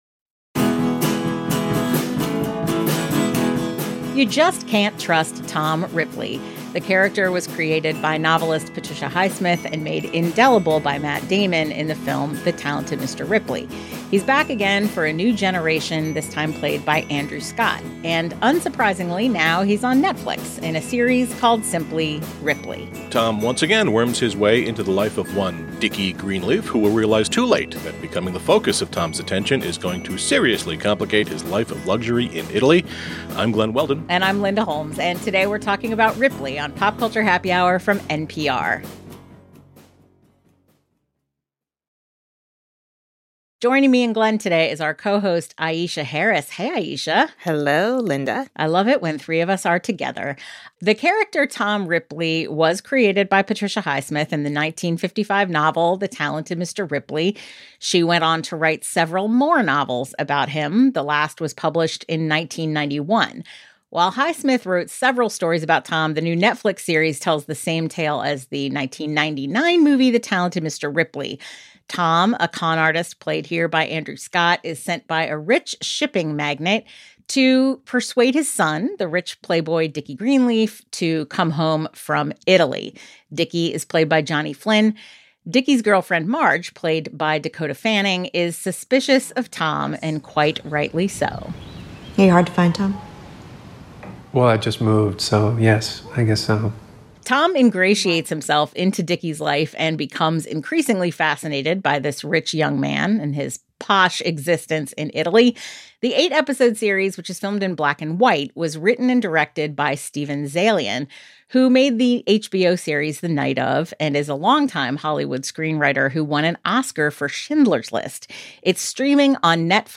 TV Review